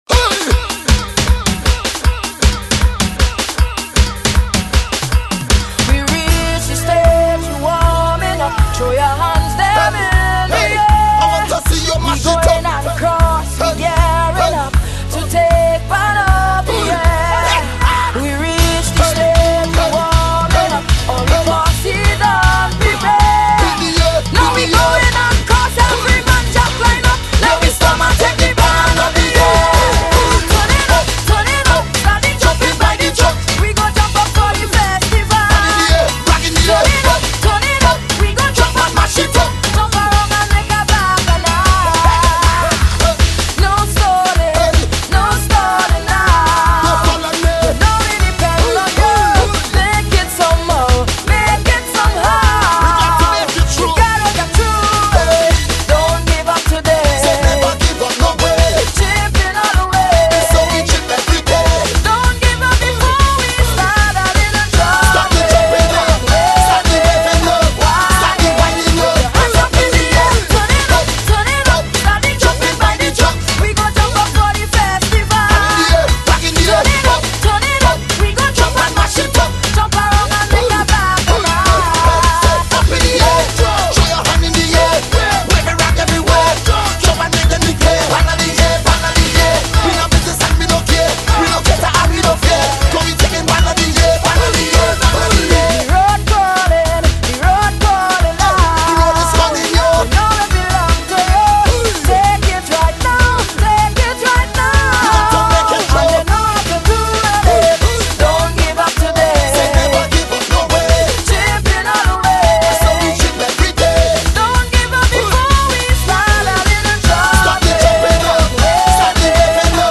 soca diva